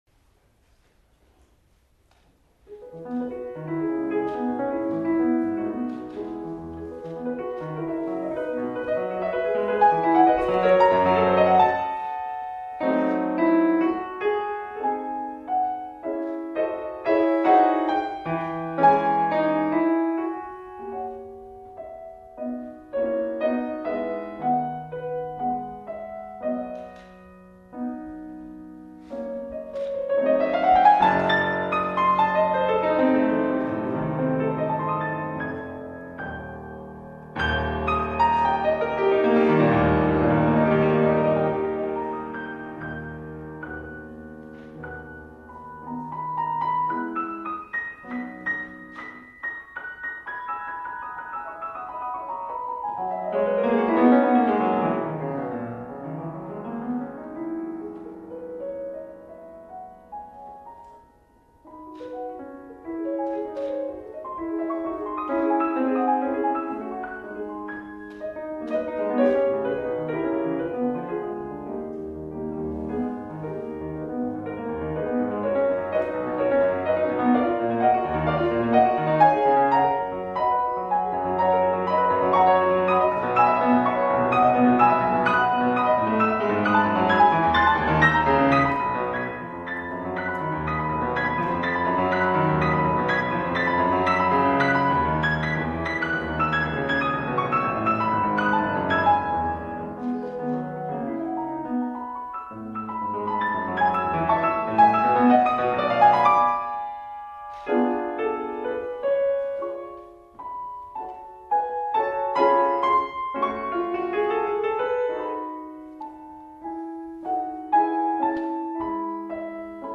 First concert of the Beethoven-Clementi series at the Royal Academy of Music
piano
David Josefowitz Recital Hall
1. Vivace, ma non troppo – Adagio espressivo – Tempo I – Adagio espressivo – Tempo I [mp3]
In the first movement, a lively and candid opening line is violently interrupted by an Adagio that juxtaposes contrapuntal writing with improvisatory sweeps.